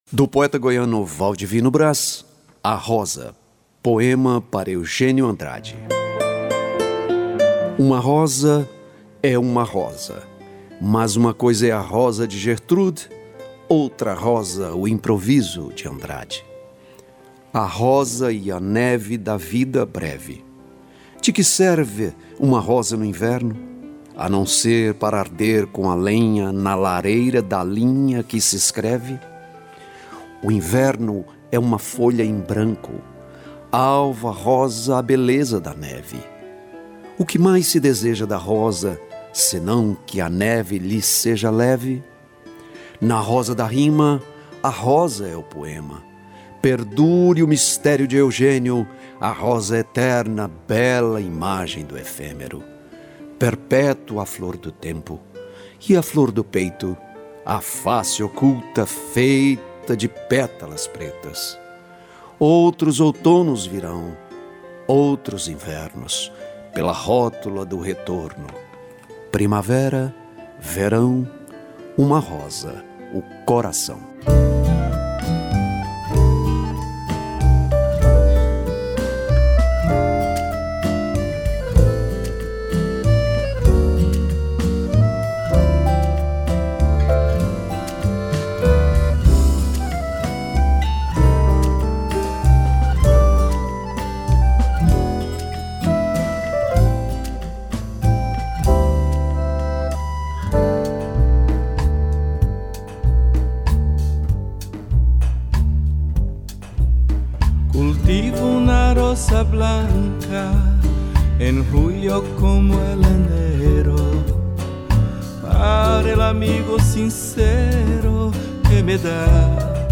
Poesia falada…Cem poemas essenciais
Projeto na Rádio Sagres-730 AM de Goiânia, GO.